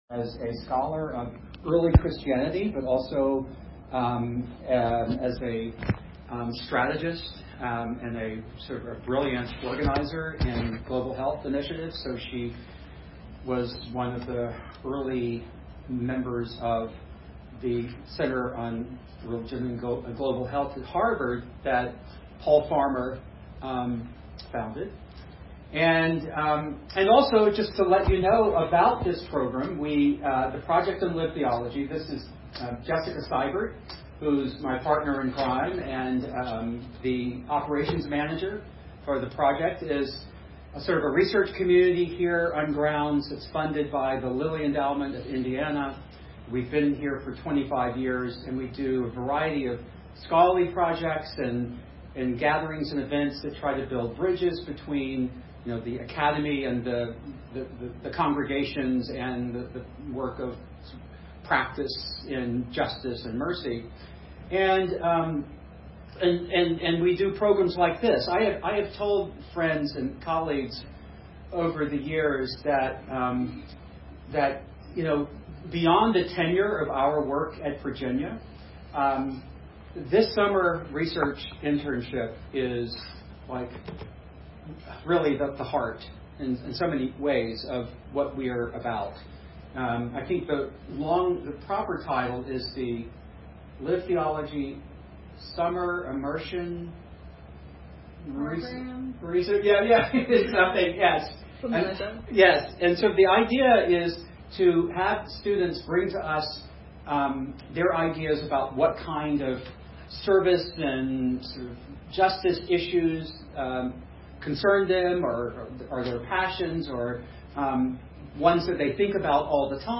Presentation
Location Recorded: Charlottesville, VA